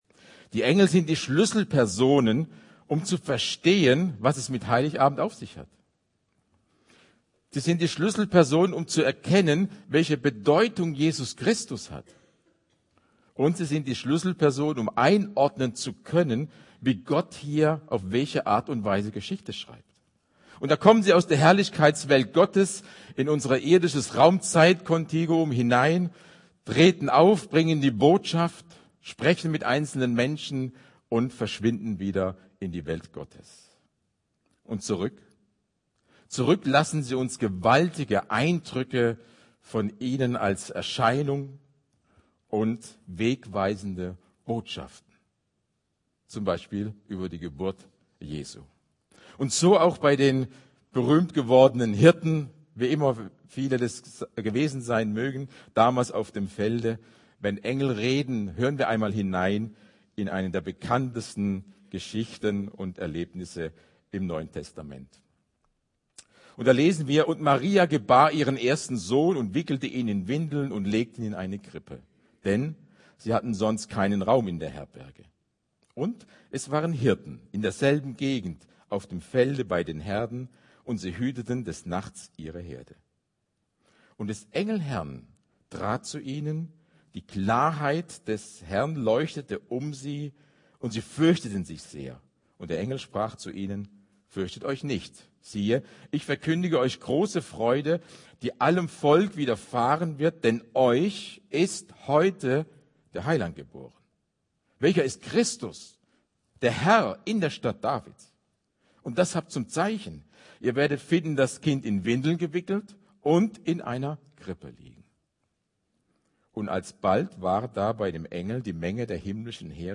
Predigt Wenn Engel reden Evangelien